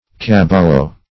Search Result for " caballo" : The Collaborative International Dictionary of English v.0.48: Caballo \Ca*bal"lo\ (k[.a]*v[aum]l"y[-o]; 220), n. [Written also cavallo .]